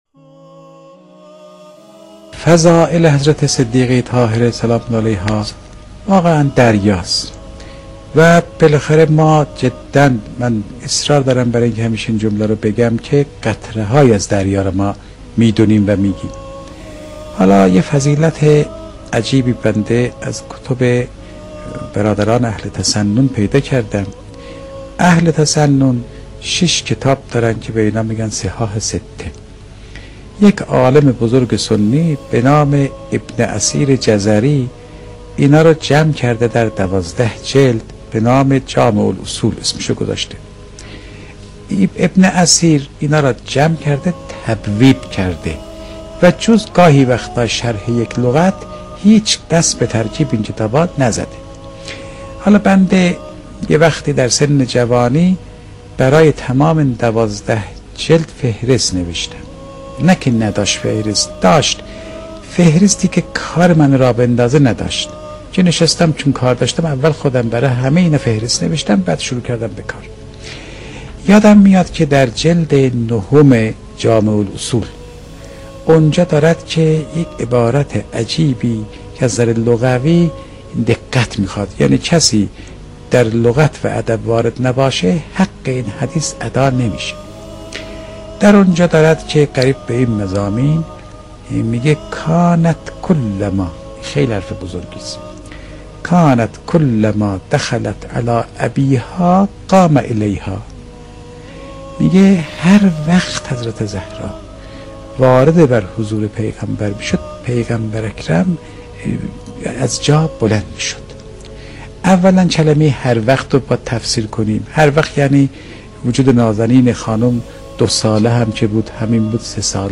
در ادامه سخنرانی آیت‌الله فاطمی نیا تقدیم مخاطبان گرامی ایکنا می شود.